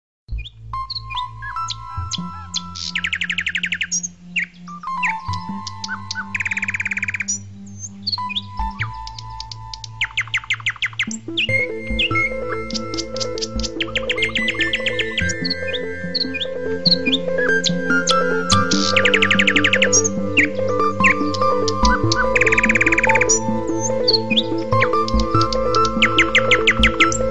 • Качество: 128, Stereo
птицы
лес